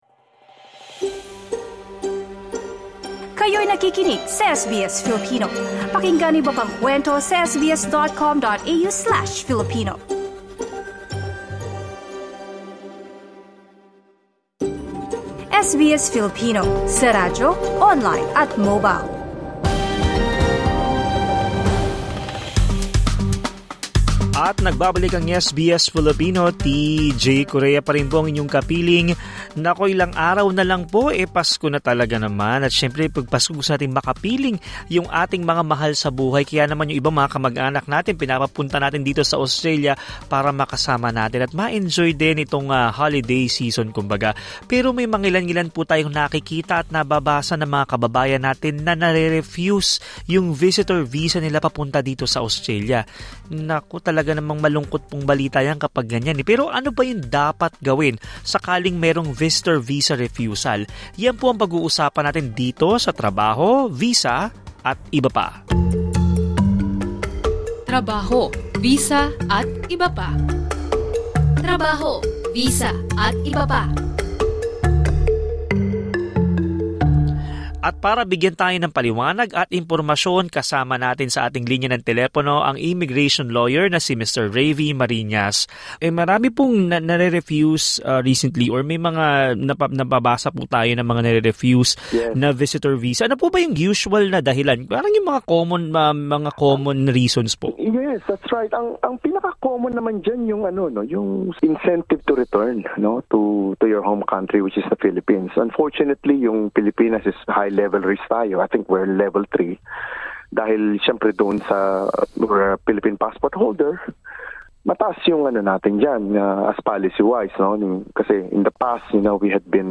In this episode of ‘Trabaho, Visa atbp.’, an immigration lawyer discussed what to do next when your visitor visa has been refused.